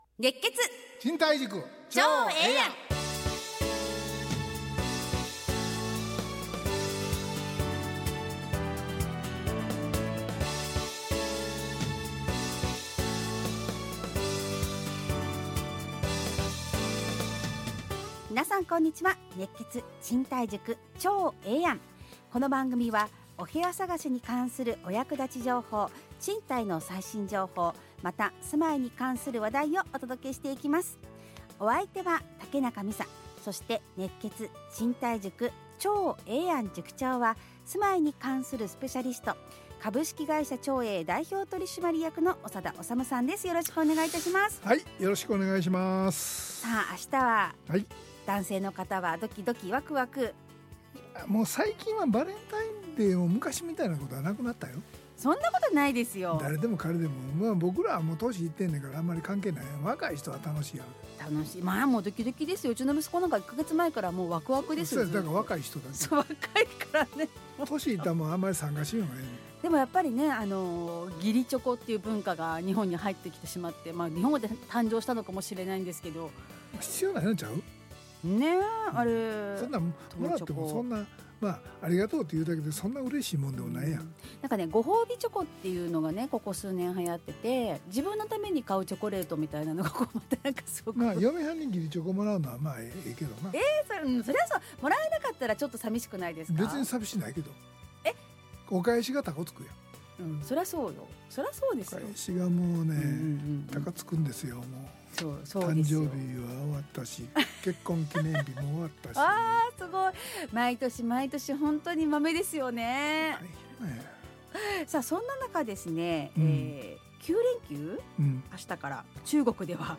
ラジオ放送 2026-02-13 熱血！